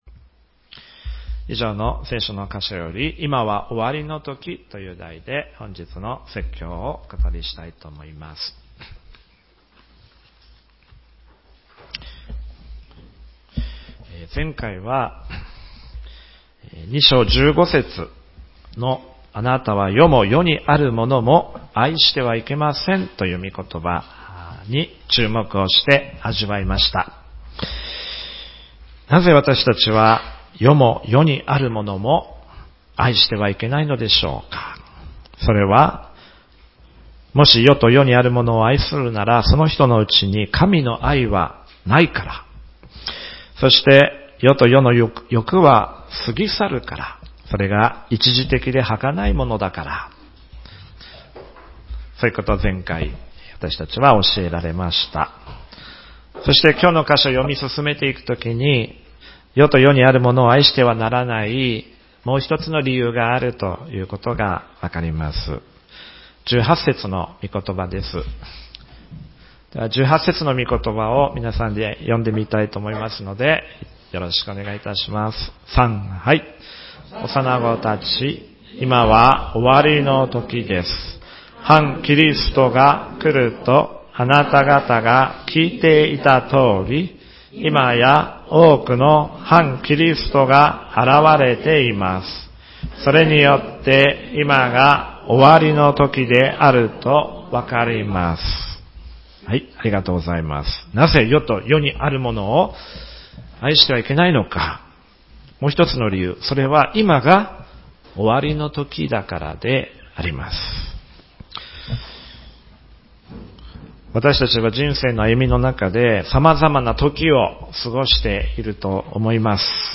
ヨハネ第一の手紙2章18－21節 説教内容筆耕 以上の聖書の箇所より、「今は終わりの時」という題で本日の説教をお語りしたいと思います。